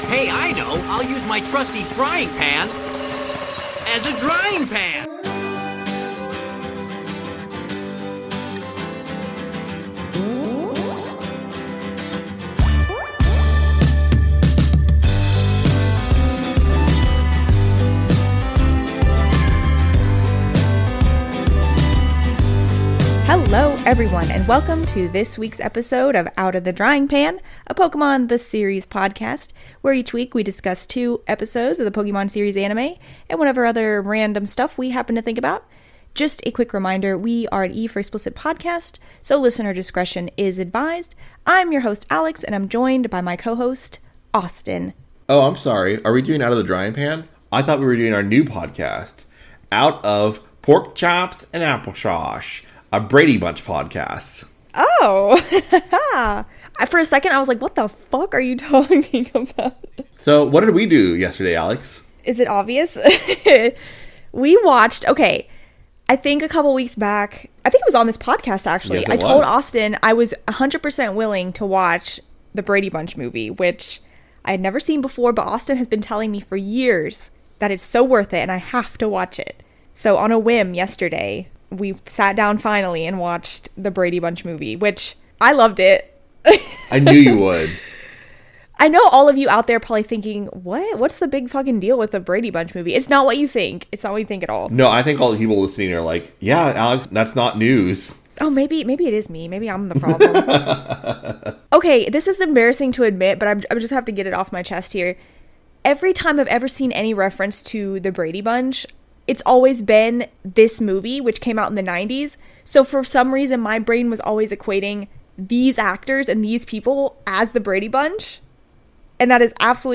A podcast where three filthy casual Millennials look back at the English dub of Pokémon the Series, and pick it apart relentlessly.